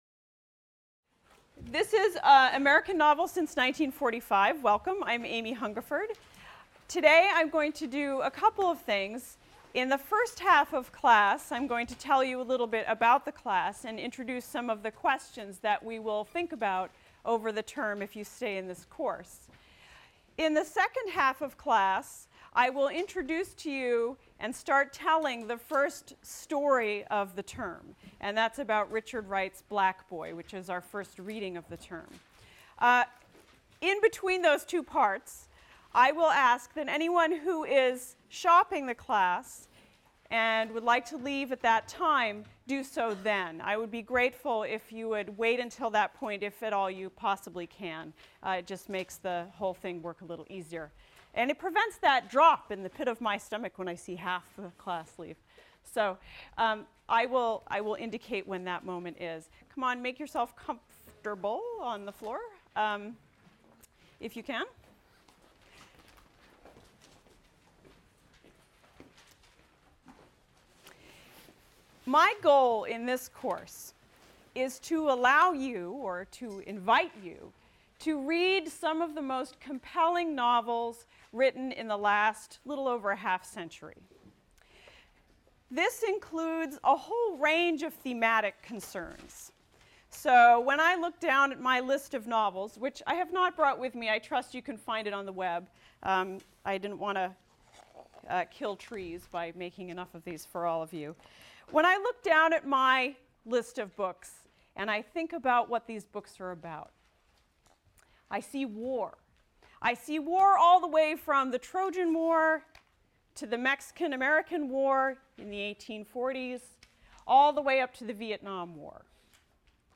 ENGL 291 - Lecture 1 - Introductions | Open Yale Courses